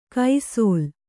♪ kai sōl